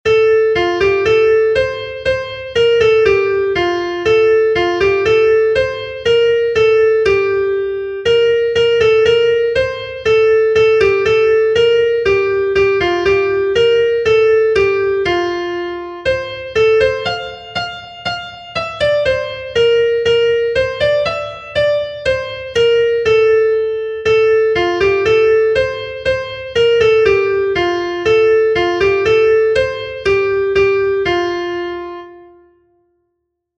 Kontakizunezkoa
Zortziko handia (hg) / Lau puntuko handia (ip)
A-B-C-A